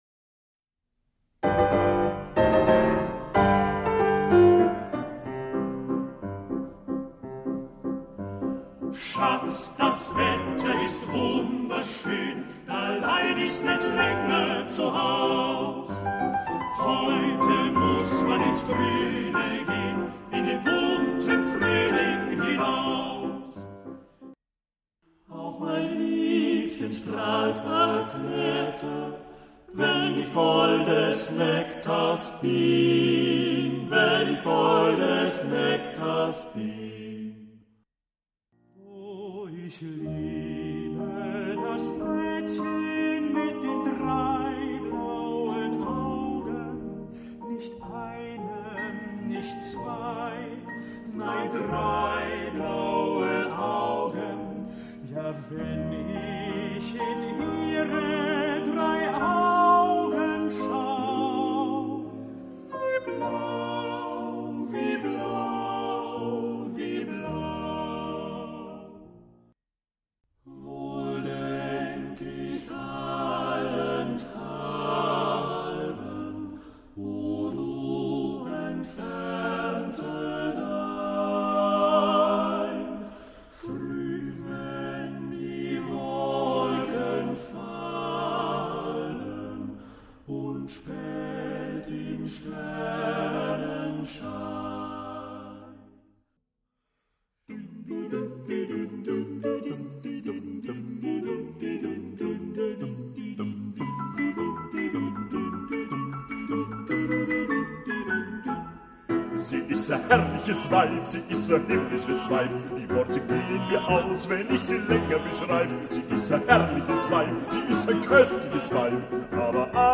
black humour and deep feeling
singphonic arrangements